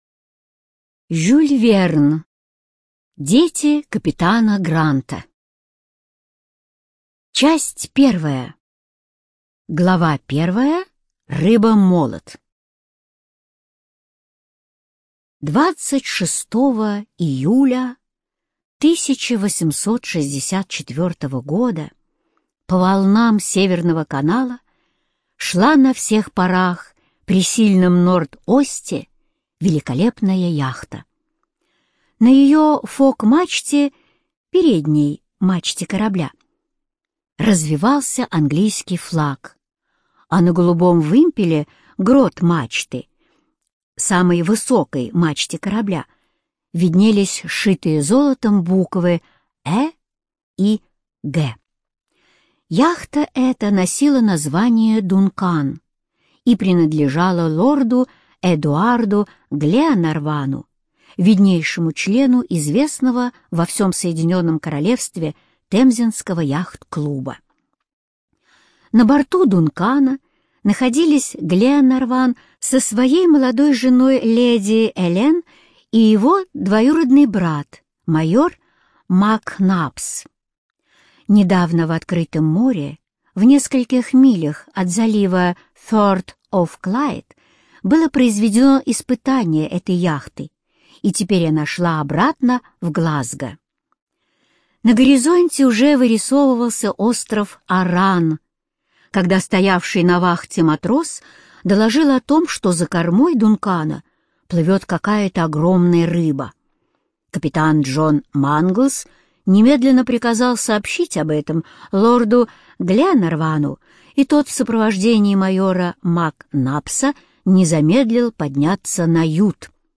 Студия звукозаписиАрдис